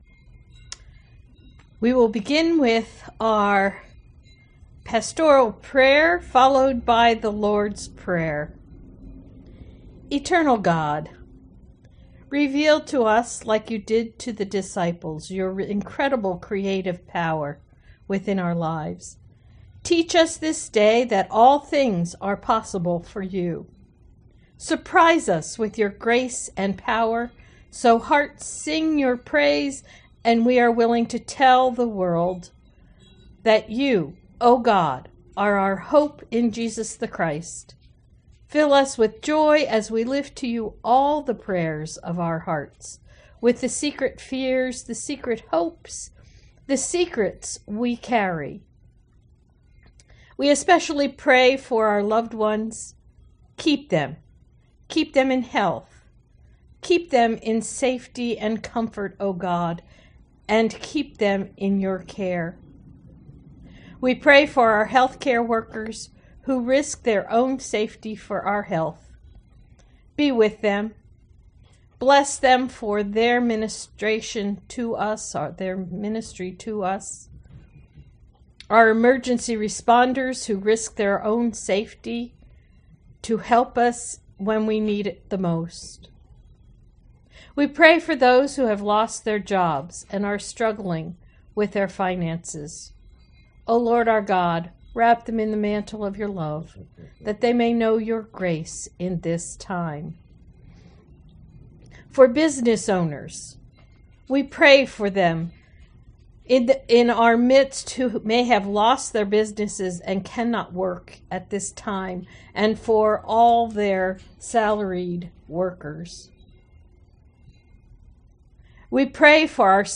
Greeting